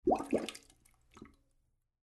Звуки поноса
Звук какашки упали в воду и появились пузырьки